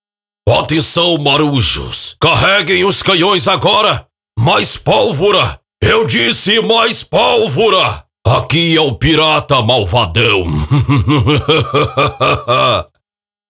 Voz PIRATA MALVADÃO
Caricata